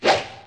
Arrow shoot.mp3